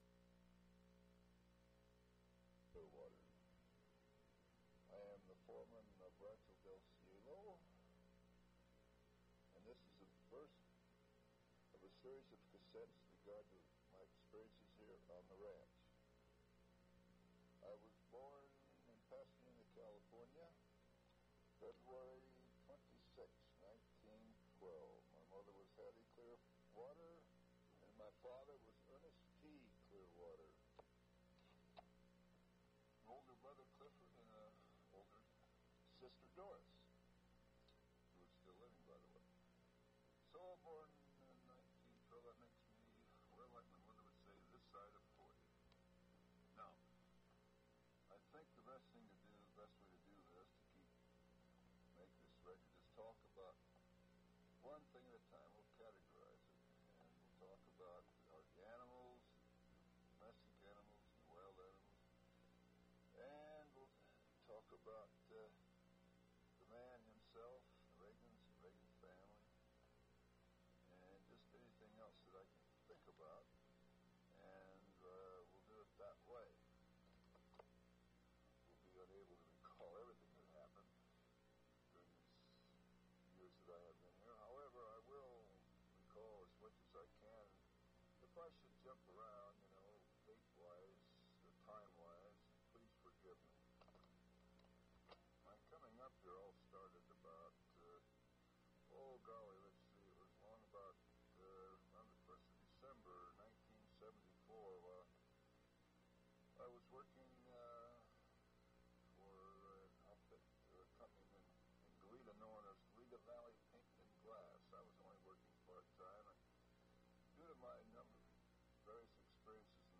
Audio Cassette Format.